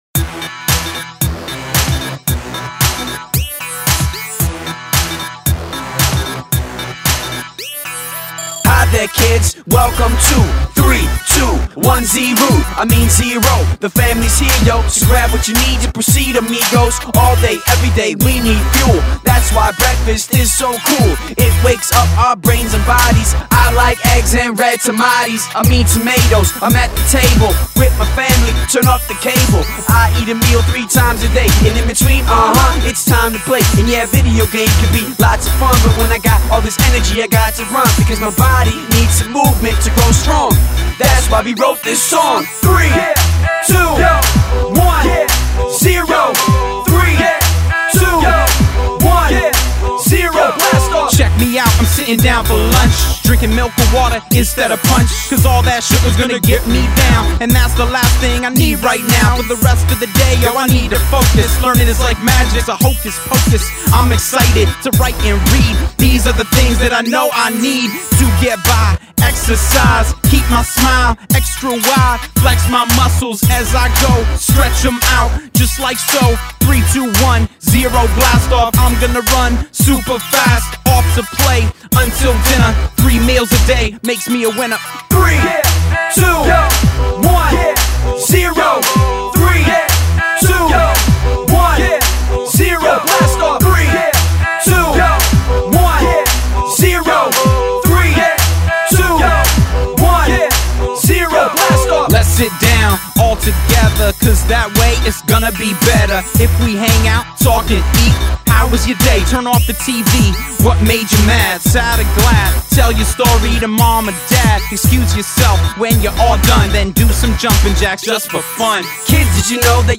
“3-2-1-0 Blast Off!” is a fun, high energy song that talks about different things students can do to be healthy. The song talks about the importance of eating three meals a day, getting exercise and spending time with family.